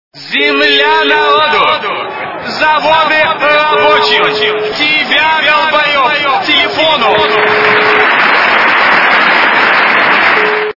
» Звуки » Люди фразы » Звонок от Ленина - Земля народу, заводы рабочим, тебя д...б к телефону!
При прослушивании Звонок от Ленина - Земля народу, заводы рабочим, тебя д...б к телефону! качество понижено и присутствуют гудки.